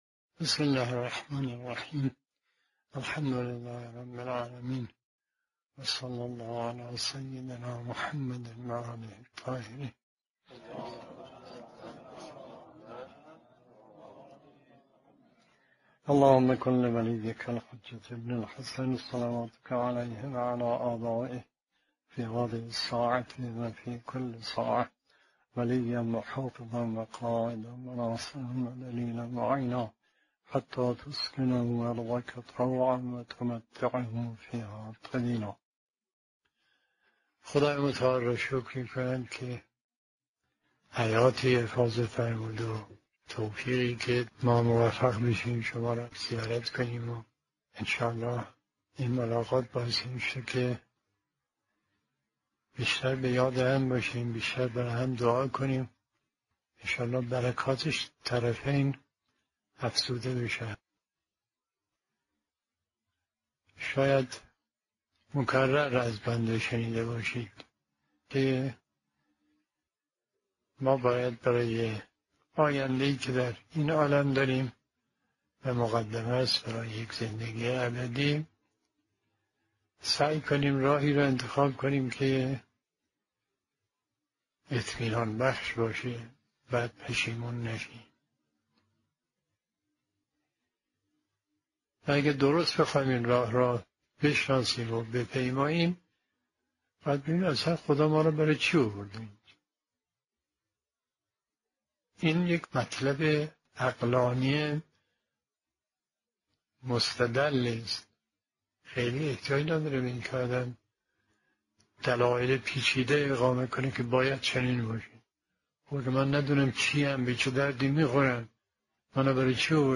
صوت سخنرانی مذهبی و اخلاقی